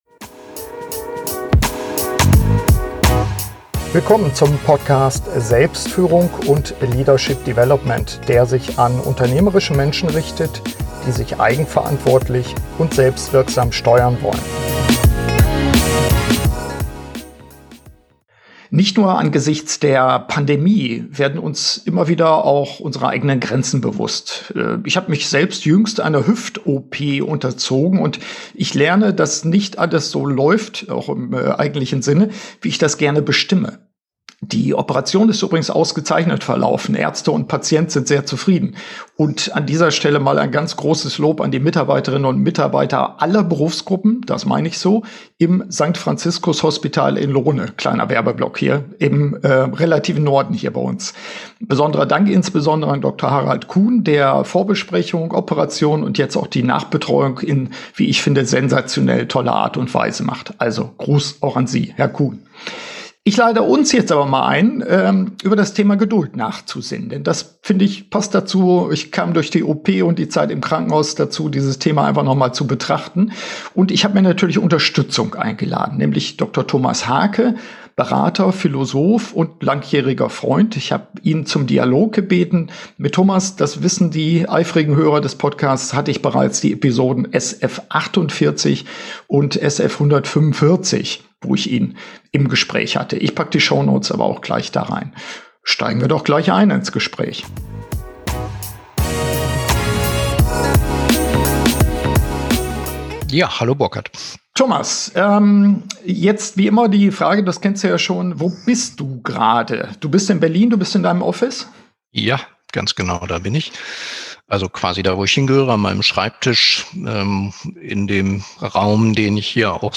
SF152 Geduld im Kontext von Führung - Update-Gespräch